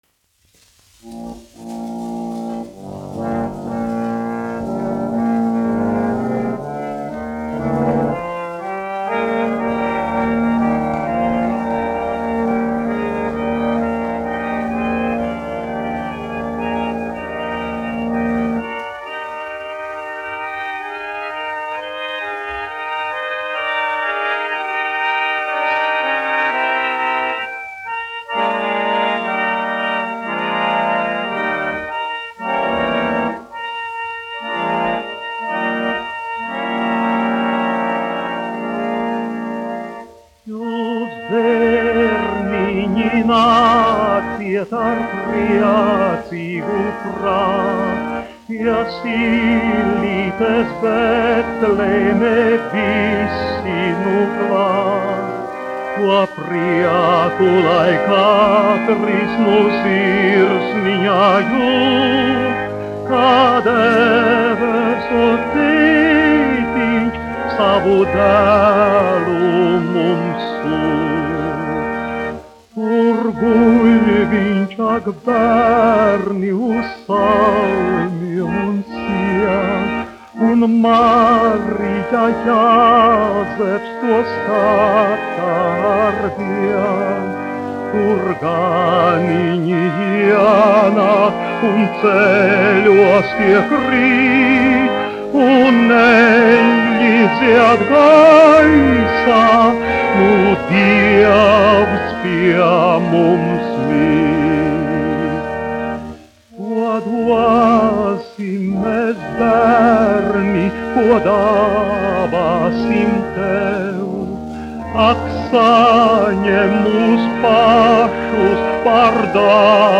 Mariss Vētra, 1901-1965, dziedātājs
Alfrēds Kalniņš, 1879-1951, instrumentālists
1 skpl. : analogs, 78 apgr/min, mono ; 25 cm
Ziemassvētku mūzika
Garīgās dziesmas ar ērģelēm
Latvijas vēsturiskie šellaka skaņuplašu ieraksti (Kolekcija)